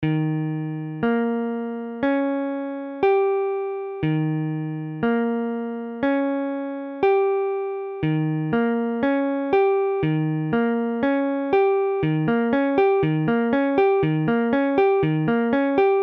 Illustration sonore : Eb7.mp3
Mesure : 4/4
Tempo : 1/4=60
A la guitare, on réalise souvent les accords de quatre notes en plaçant la tierce à l'octave.
Forme fondamentale : tonique quinte septième mineure tierce majeure
Eb7.mp3